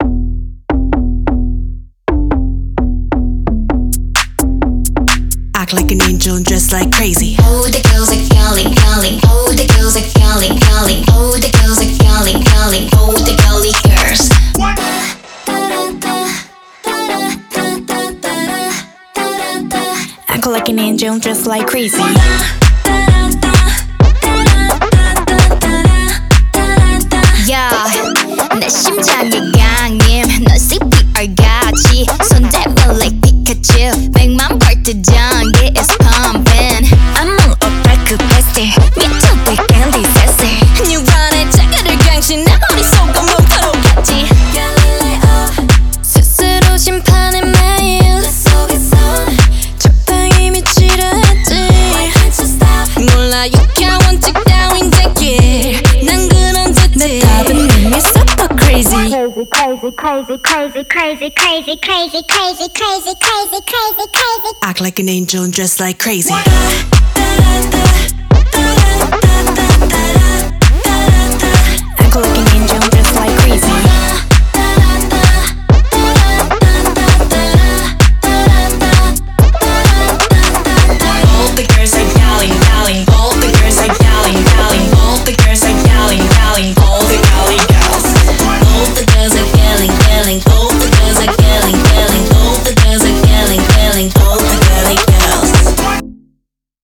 BPM130
Audio QualityMusic Cut